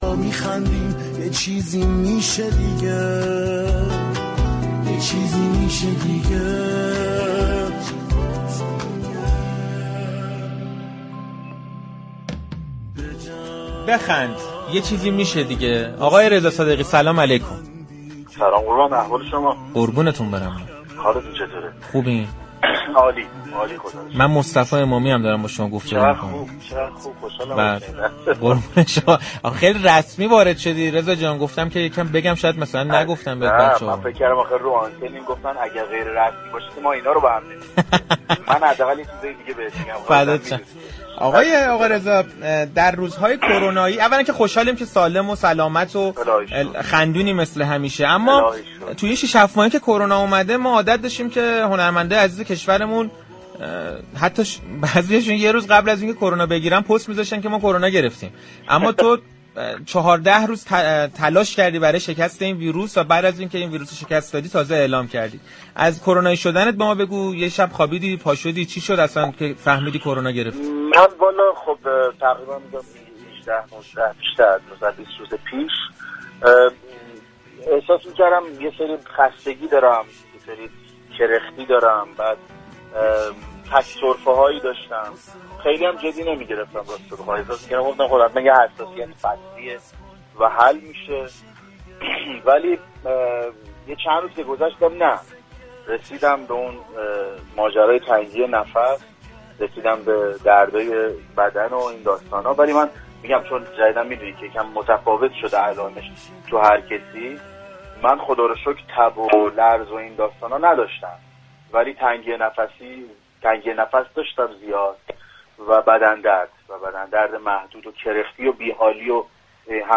رضا صادقی هنرمند محبوب كشورمان پس از دوهفته جنگ و ستیز با كرونا بالاخره بیماری خود را در گفتگو با صحنه رادیو تهران رسانه ای كرد.